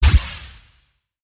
laser.wav